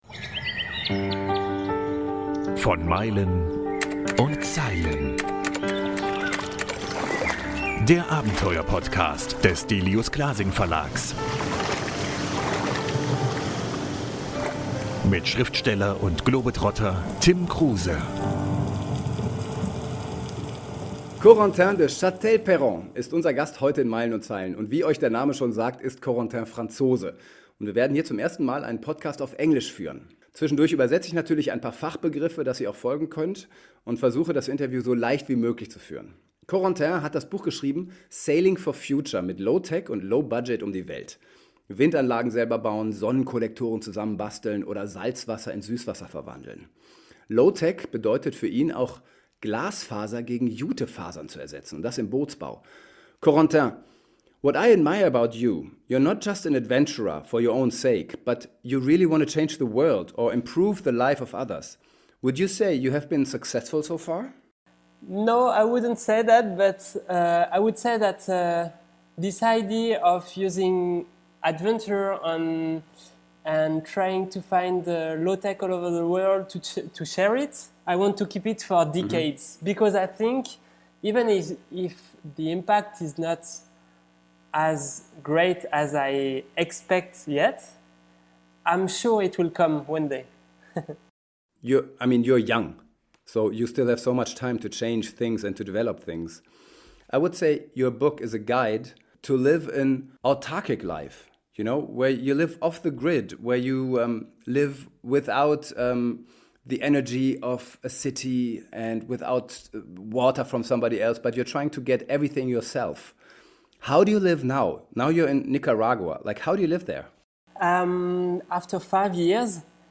Beschreibung vor 5 Jahren **Das Interview ist auf Englisch.